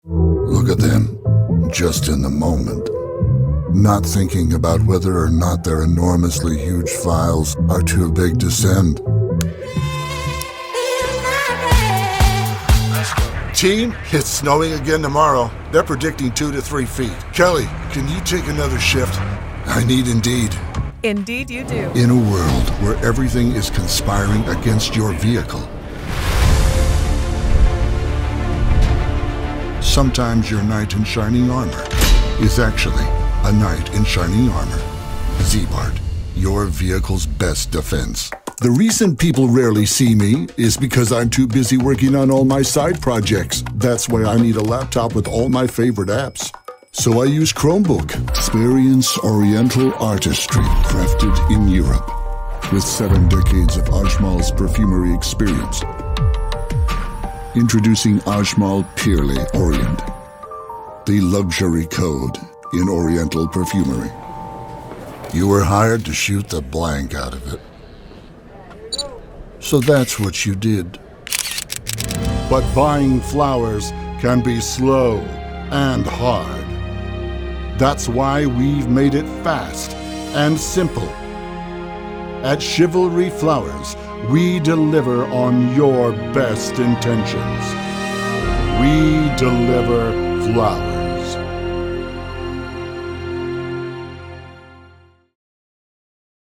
Voice Gender: Male